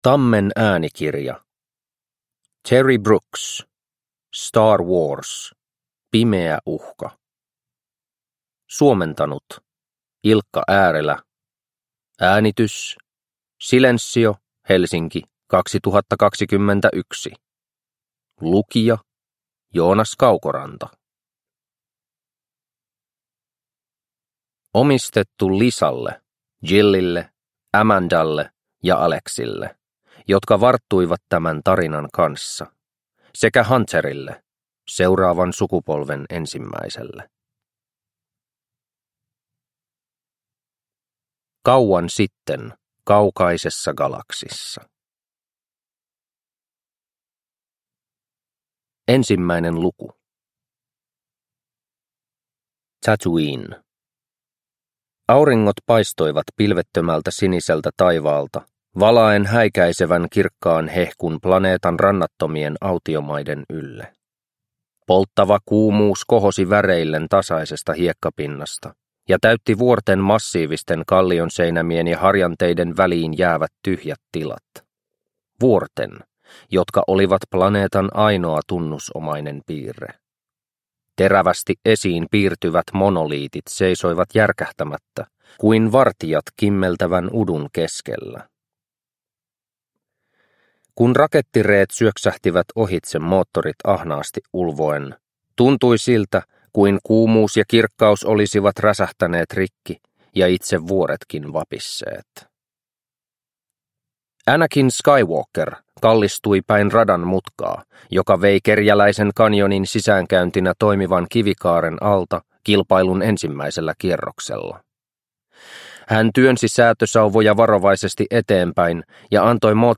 Star Wars. Pimeä uhka – Ljudbok – Laddas ner